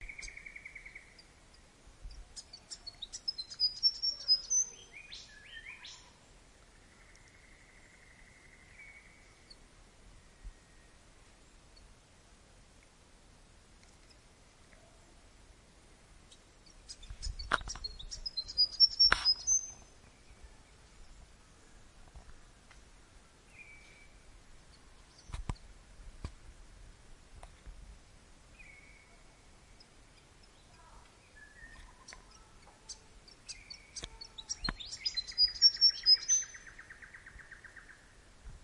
雨 " 小雨
描述：另一个在苏格兰的录音，这次使用了索尼HiMD迷你光碟录音机MZNH 1的PCM模式和Soundman OKMII与A 3适配器。人们可以听到雨滴落在我所站的树叶上的声音。
在短暂的录制过程中，远处有一辆汽车驶过。
标签： 双耳 现场记录 森林
声道立体声